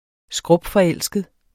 Udtale [ ˈsgʁɔbfʌˈεlˀsgəð ] Betydninger meget forelsket